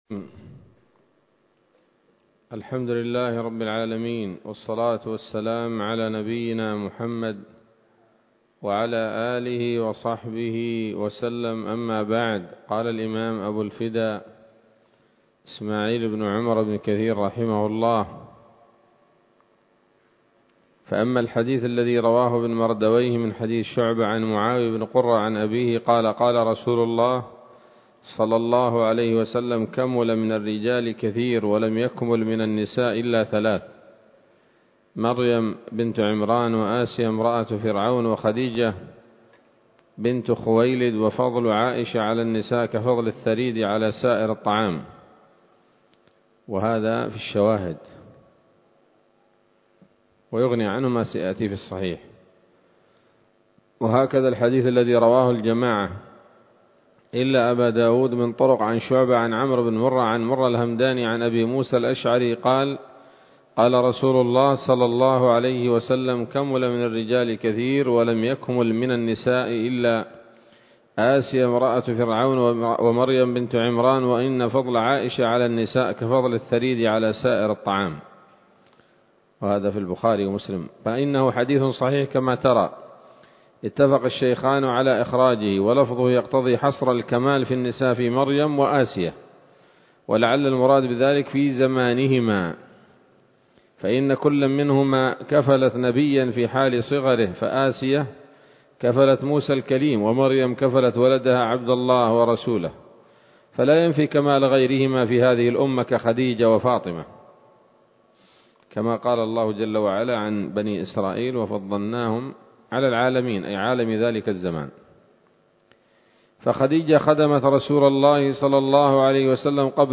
‌‌الدرس الأربعون بعد المائة من قصص الأنبياء لابن كثير رحمه الله تعالى